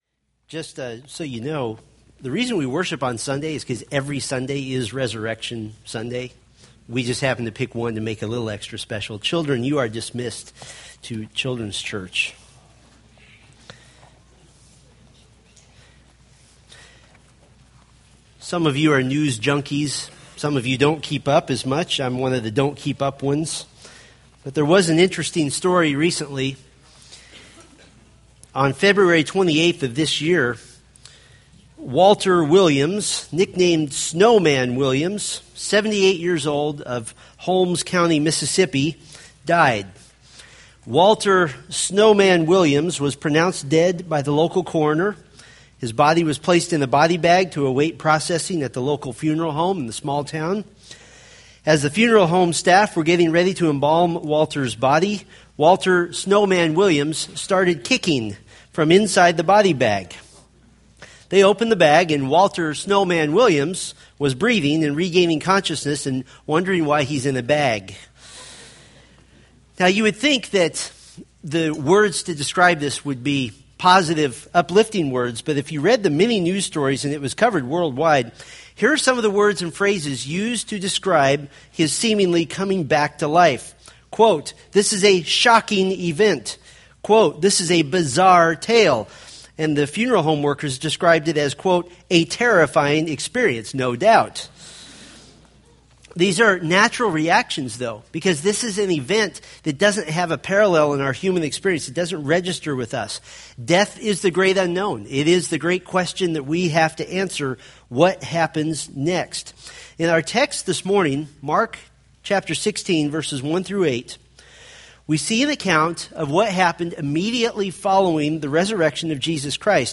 Mark Sermon Series